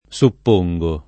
DOP: Dizionario di Ortografia e Pronunzia della lingua italiana
supporre